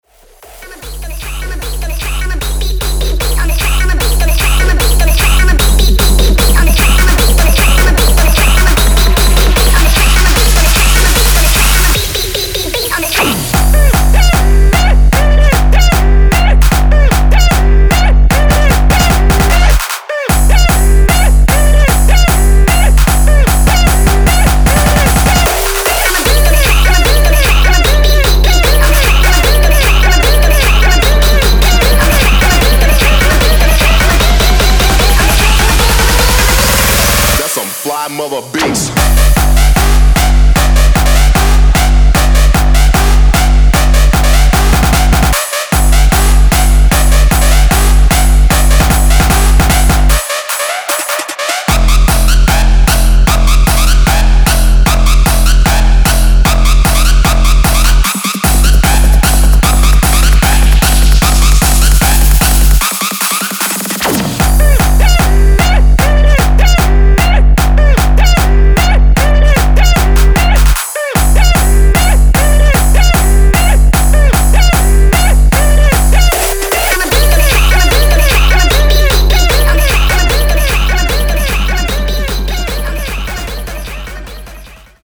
Electronic
Techno, Hard Techno, Hardstyle, Jumpstyle